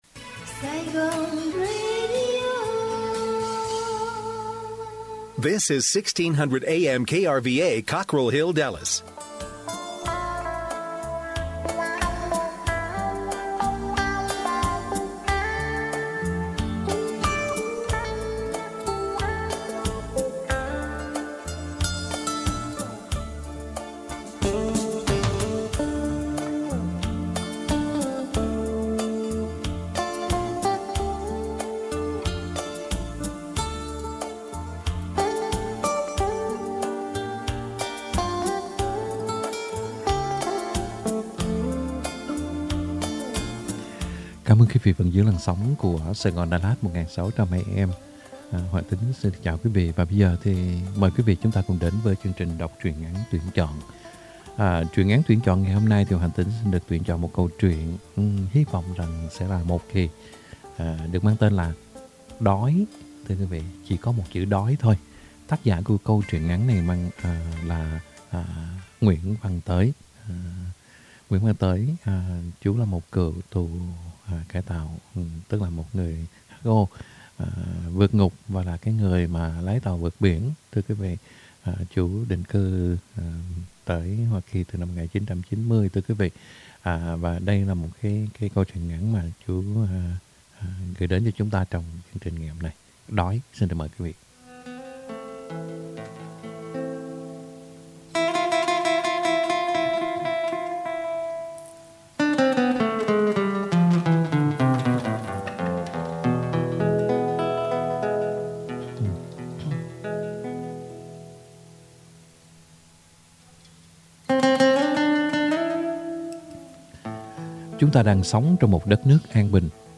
Đọc Truyện Ngắn = Đói - 11/23/2021 .